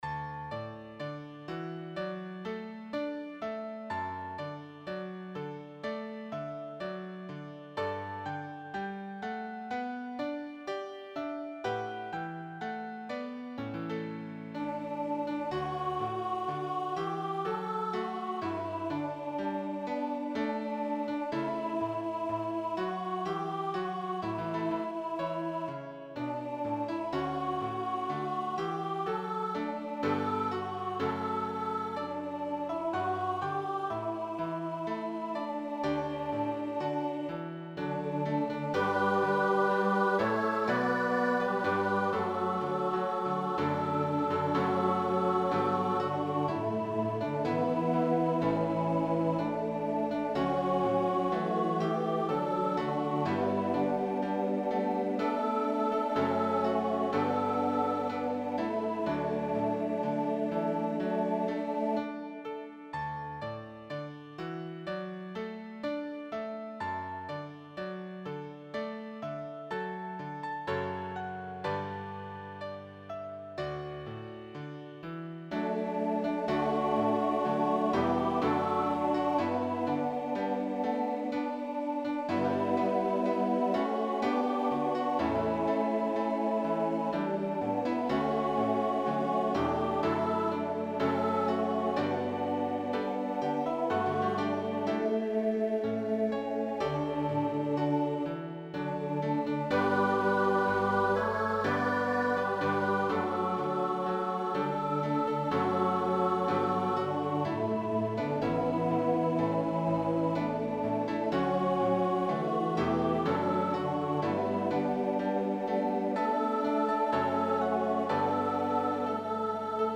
Voicing/Instrumentation: SATB We also have other 37 arrangements of " Brightly Beams Our Father's Mercy ".